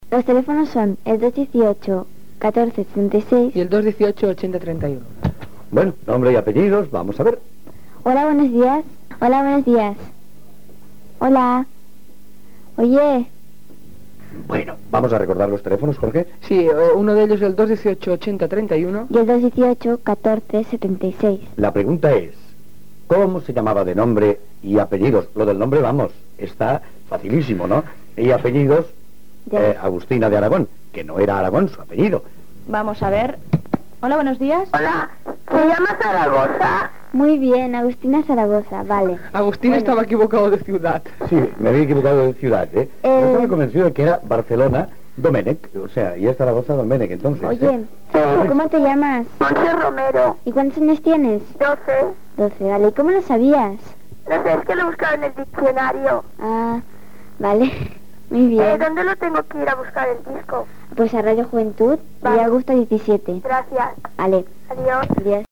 Telèfons del concurs, pregunta i participació telefònica d'una oïdora.
Infantil-juvenil